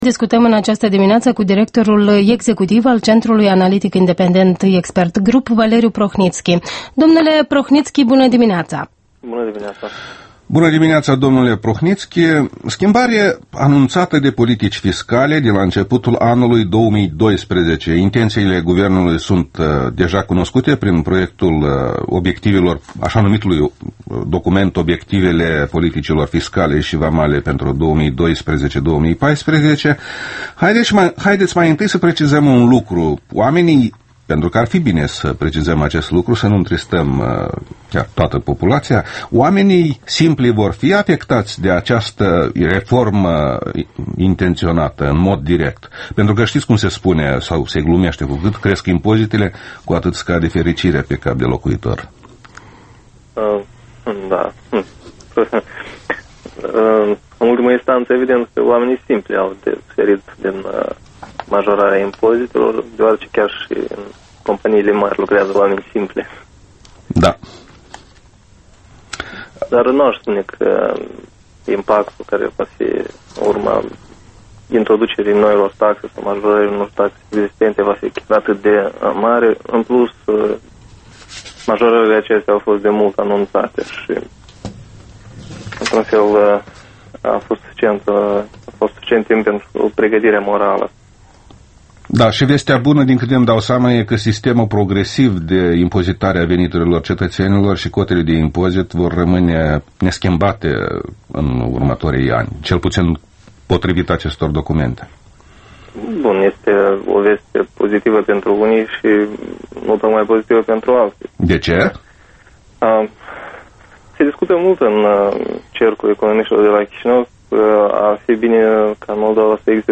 Interviul matinal EL: